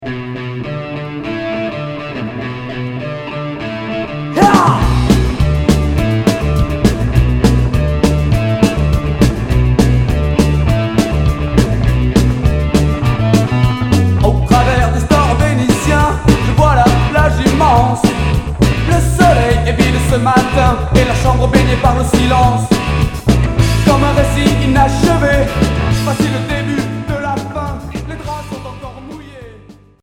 Rock Unique 45t retour à l'accueil